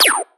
DDW4 SFX LASER GUN.wav